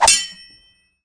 shuriken-hit-hard-01.ogg